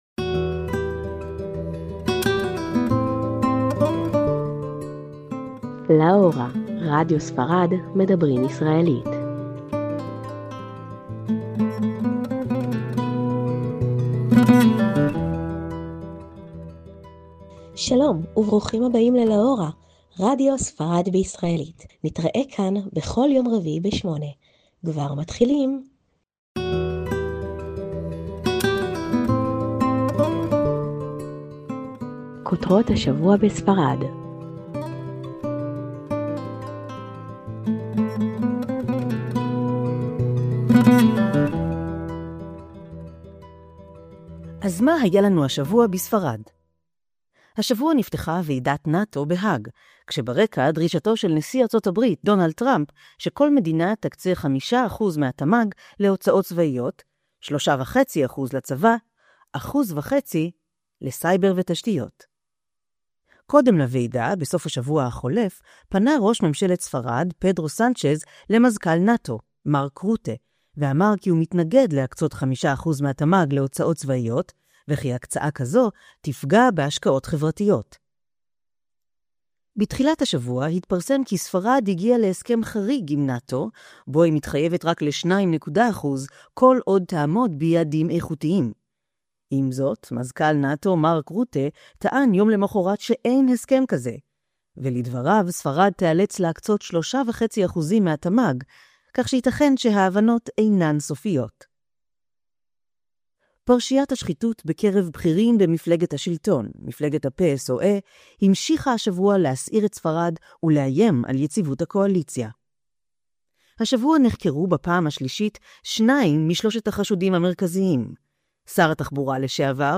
תכנית רדיו בעברית לטובת הישראלים בספרד והיום